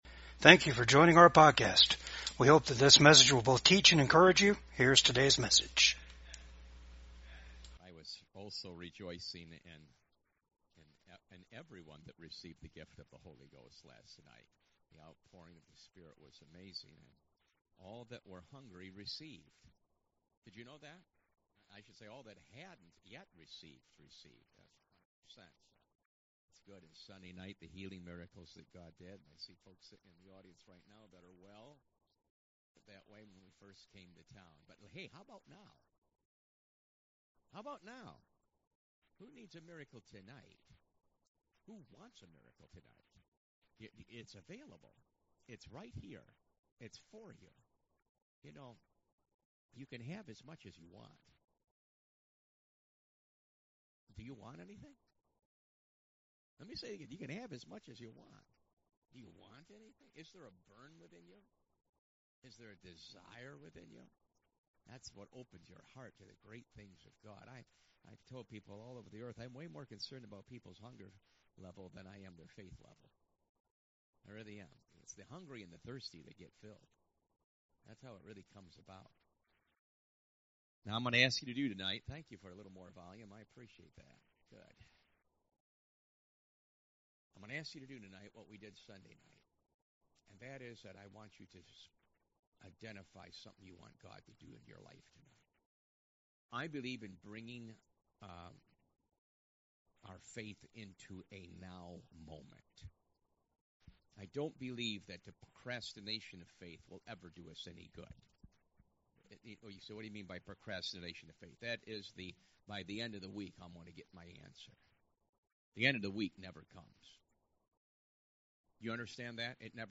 1 Corinthians 2:9-12 Service Type: REFRESH SERVICE YOU ARE COMPLETE IN CHRIST.